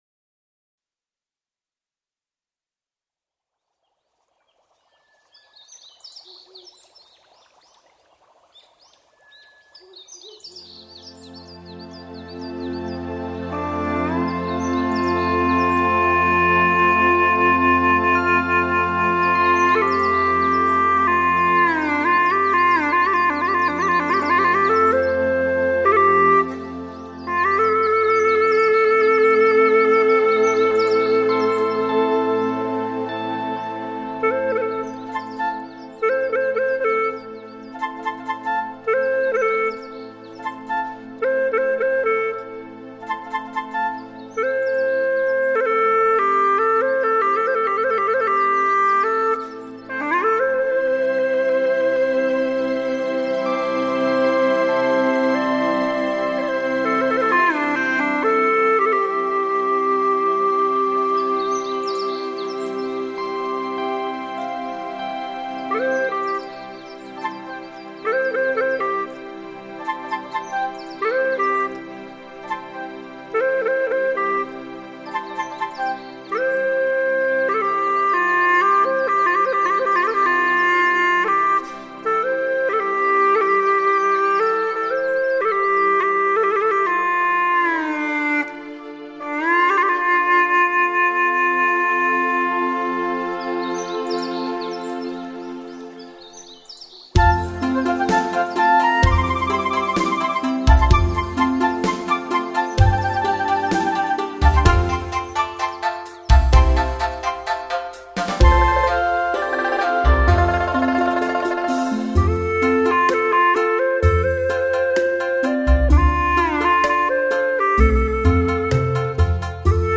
调式 : F
独奏